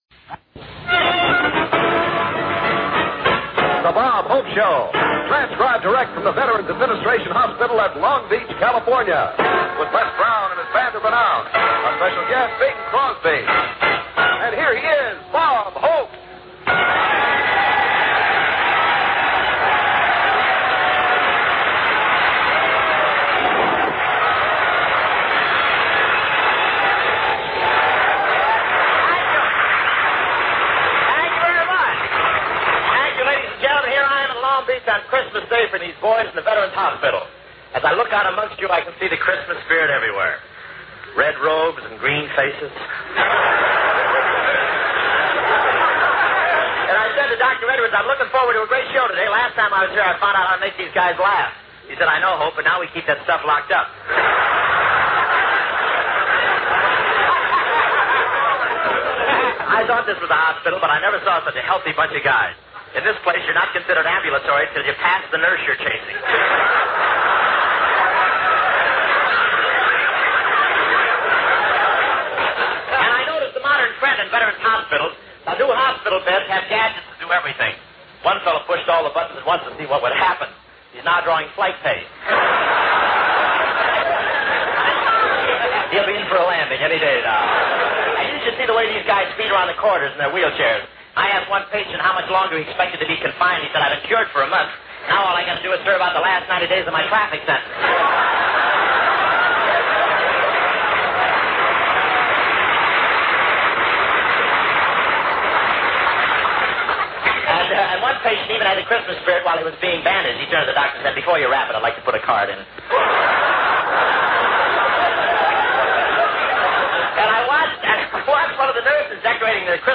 OTR Christmas Shows - From Long Beach, California Veteran\'s Hospital - Bing Crosby - 1950-12-25 AFRS The Bob Hope Show